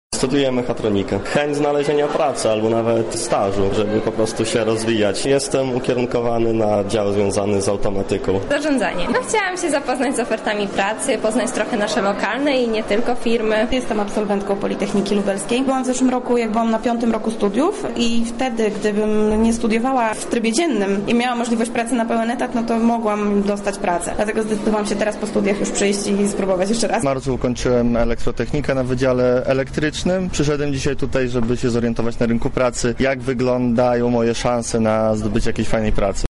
Zapytaliśmy uczestników, z jakim nastawieniem wzięli udział w targach:
Relacja z wydarzenia